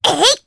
Rephy-Vox_Attack1_jp.wav